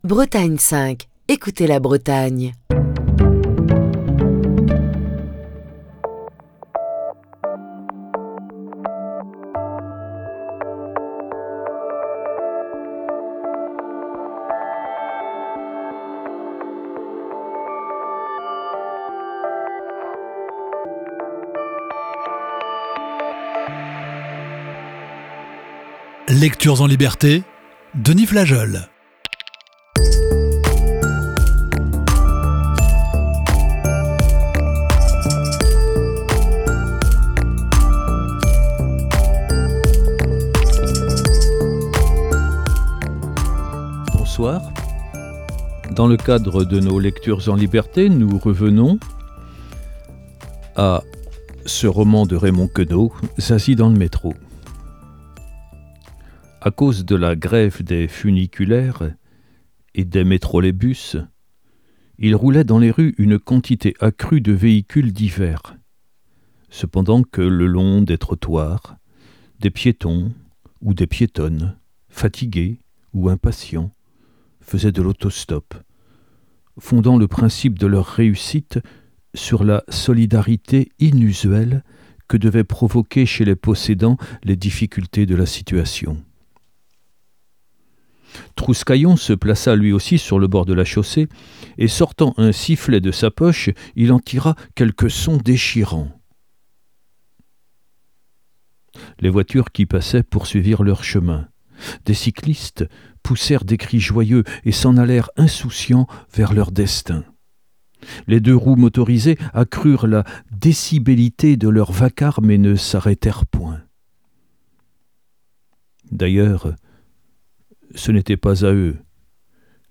Émission du 2 janvier 2024.